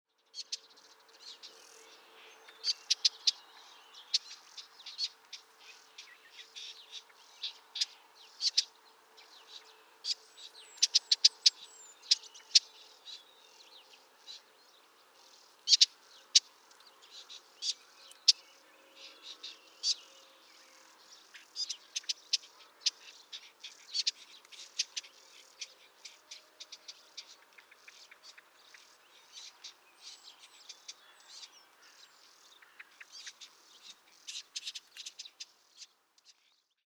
reedbeds, bush and scrub areas and sandy slopes.
PFR10128, 1-11, 140806, European Stonechat, juv, Common Whitethroat, family, calls, Germany